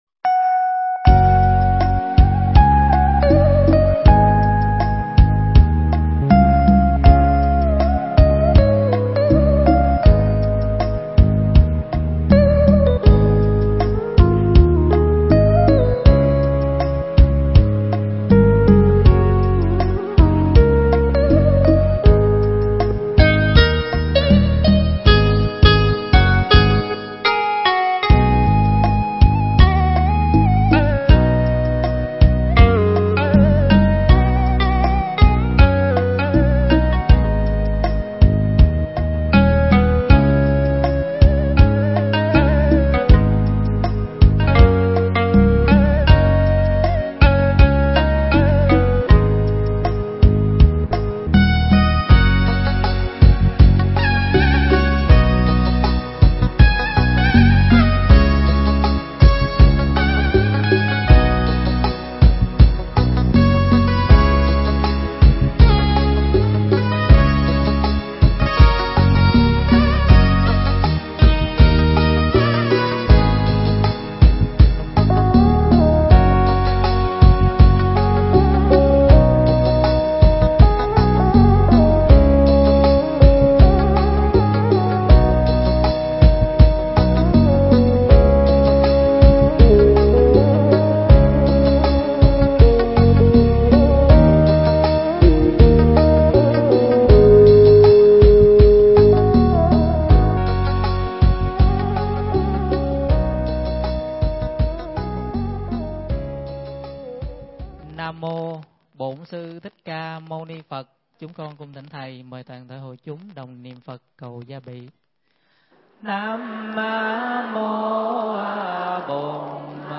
Mp3 Thuyết Giảng Ai Là Thầy Ta?
tại Tu Viện Tường Vân, nhân lê Hạ Nguyên (rằm tháng 10 AL)